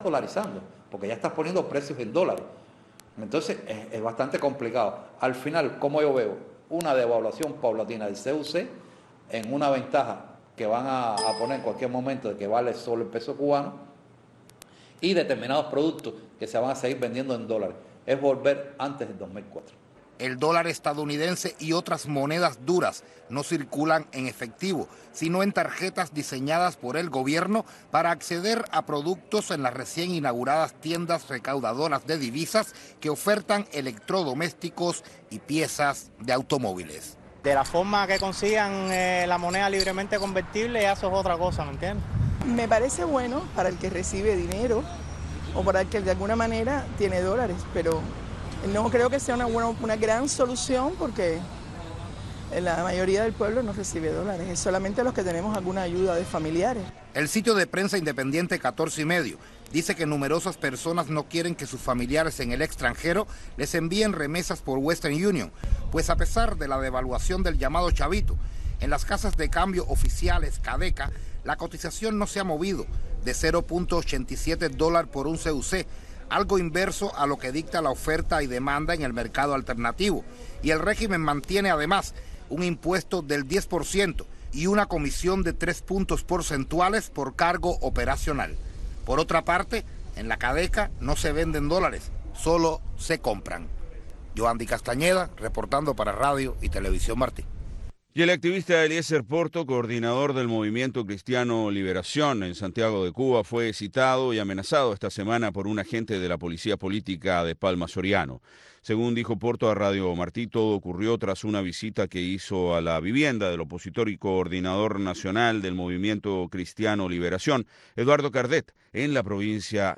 Programa humanista, preservador de buenas costumbres, reflexivo, aderezado con música y entrevistas. Las artes, el deporte, la ciencia, la política, e infinidad de tópicos, caben en este programa que está diseñado para enaltecer nuestras raíces, y para rendir tributo a esa bendita palabra que es Familia.